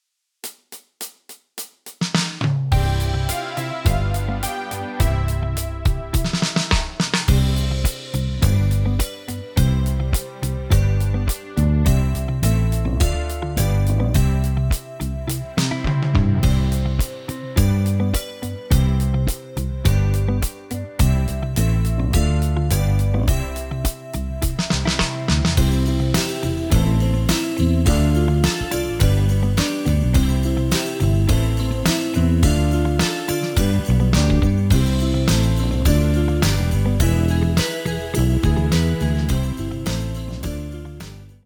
Duża dynamika, sami sprawdźcie.
Tonacja C-dur.